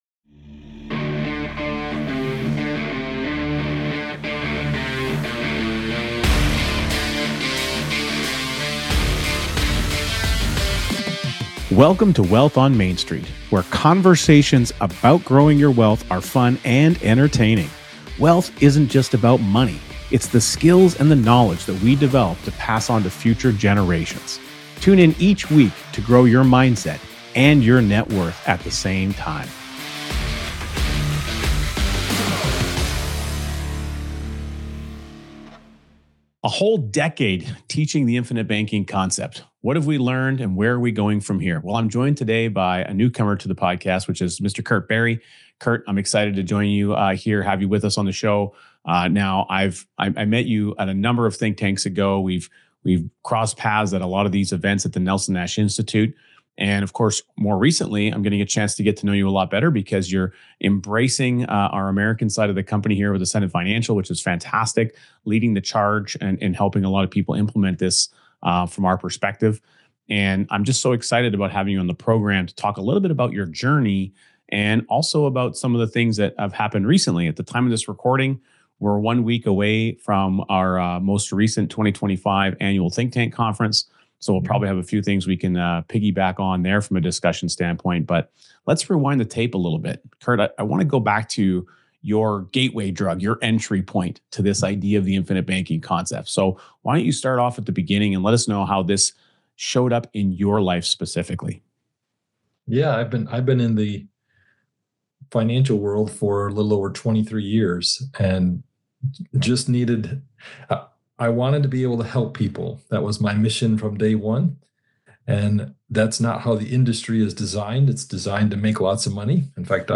Tune in for an eye-opening conversation!